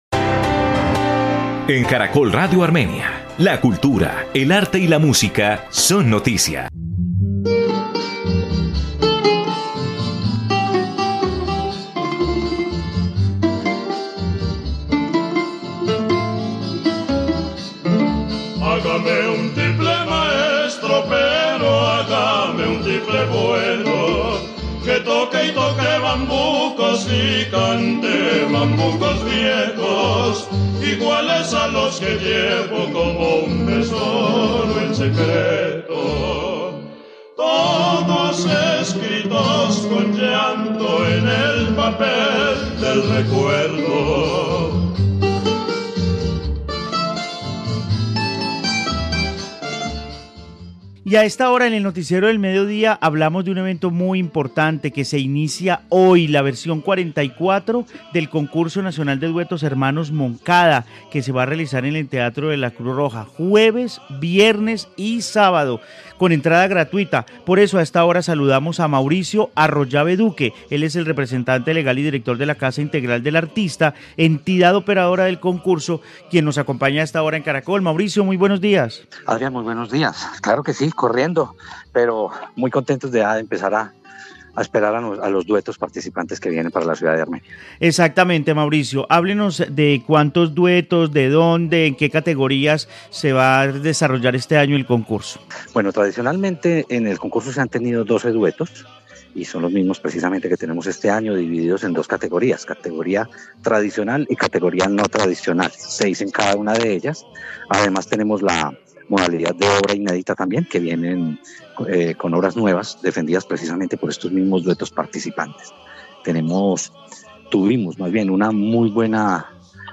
Informe Concurso Nacional de Duetos Hermano Moncada en Armenia